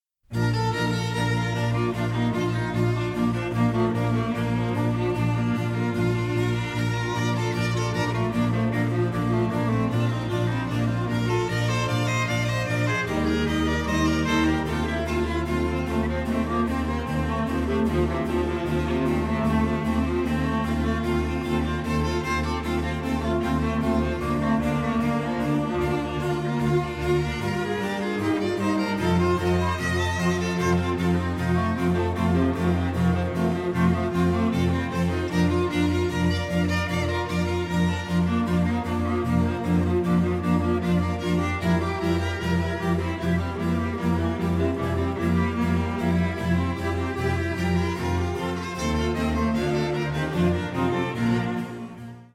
in B flat
viola I
cello I
double bass
harpsichord